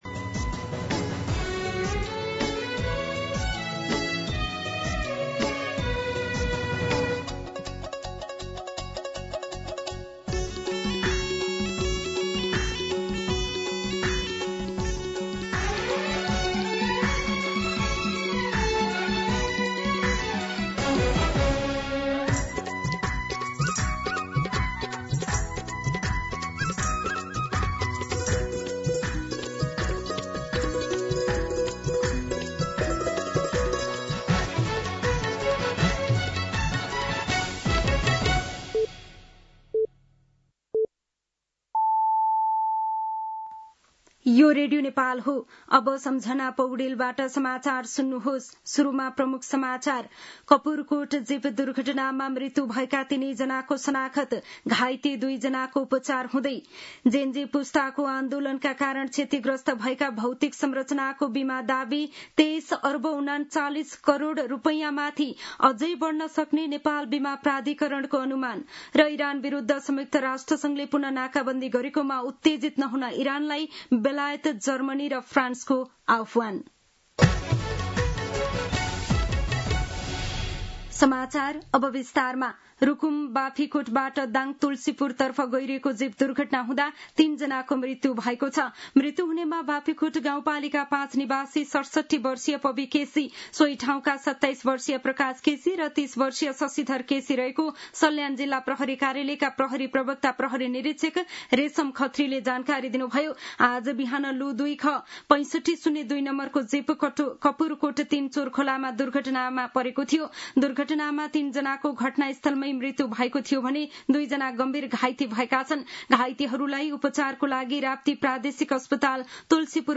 An online outlet of Nepal's national radio broadcaster
दिउँसो ३ बजेको नेपाली समाचार : १२ असोज , २०८२
3-pm-News-06-12-.mp3